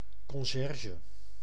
Ääntäminen
Synonyymit pedel koster huisbewaarder huisbewaarster bode portier Ääntäminen Tuntematon aksentti: IPA: /kɔn.ˈʒɛr.ʒə/ Haettu sana löytyi näillä lähdekielillä: hollanti Käännöksiä ei löytynyt valitulle kohdekielelle.